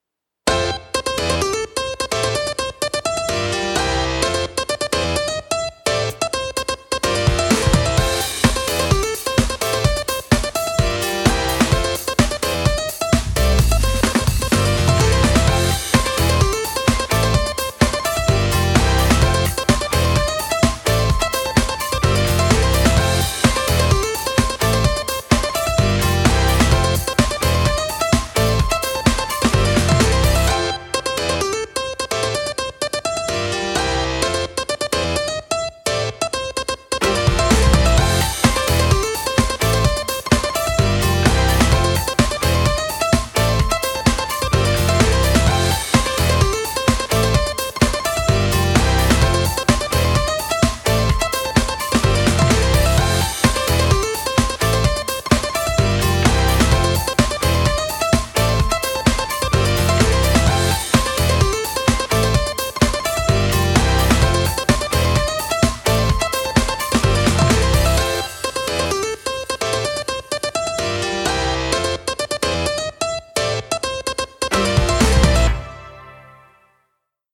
リリースカットピアノ/かっこいい/おしゃれ